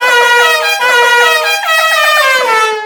mvm_tank_horn.wav